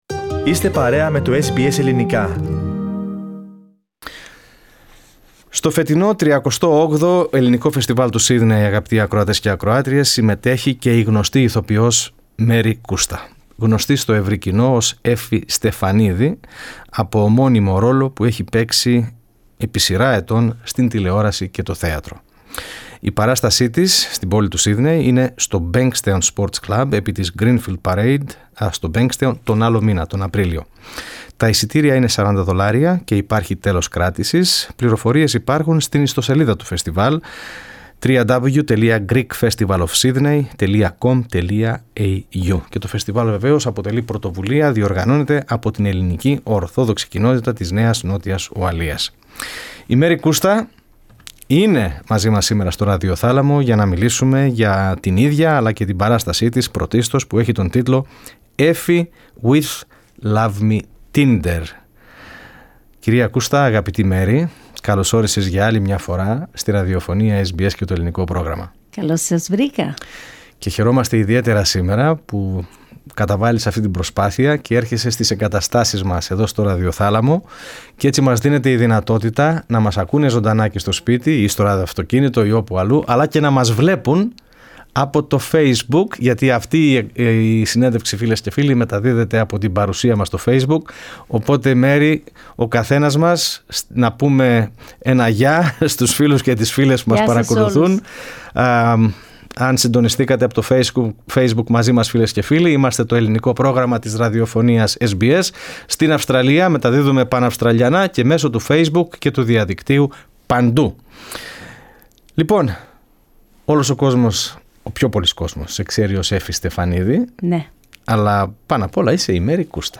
Με αφορμή τη θεατρική κωμωδία της Effie in Love Me Tinder που θα παρουσιαστεί στο φετινό 38ο Ελληνικό Φεστιβάλ του Σύδνεϋ, η Μαίρη Κούστα βρέθηκε στον ραδιοθάλαμο του Ελληνικού Προγράμματος της SBS, δίνοντας στους ακροατές μια γεύση από την παράστασή της. Μίλησε για την Έφη φυσικά αλλά και για τη... Μαίρη σε μια αποκαλυπτική συνέντευξη.